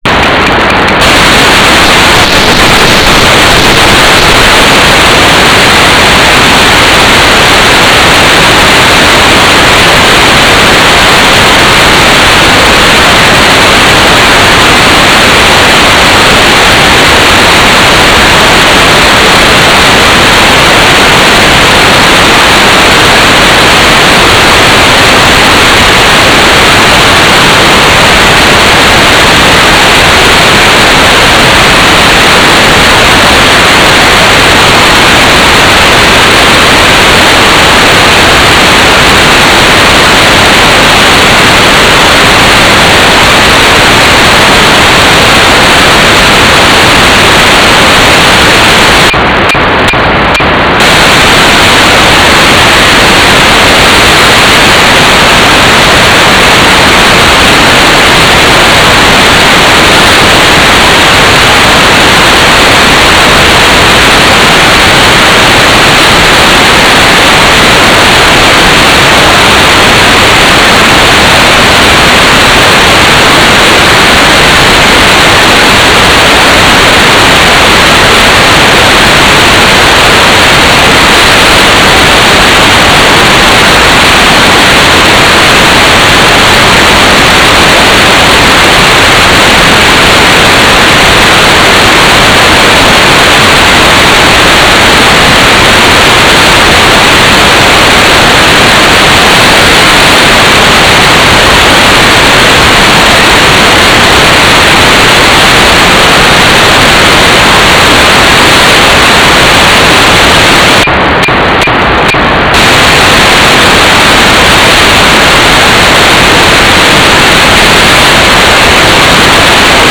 "transmitter_description": "Mode U - GMSK 4k8 AX.25 TLM",